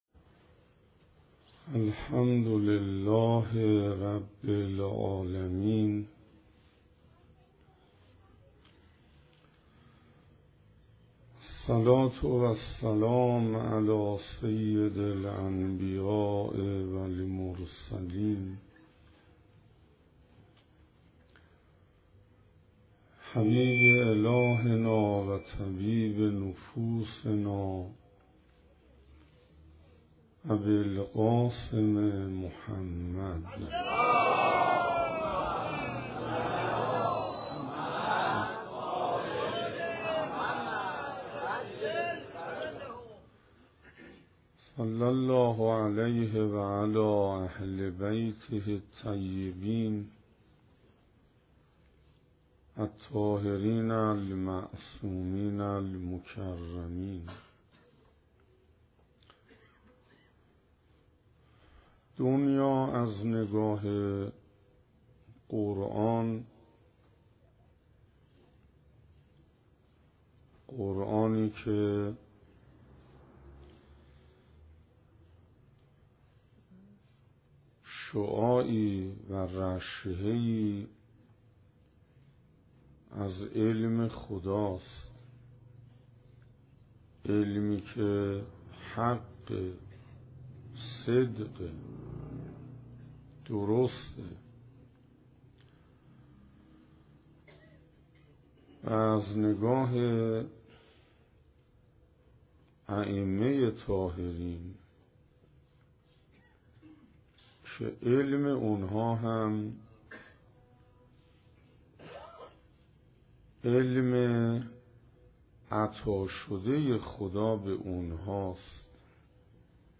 روش قرآنی برخورد با دنیا - جلسه دوم - محرم 1435 - حسینیه صاحب‌الزمان(عج) -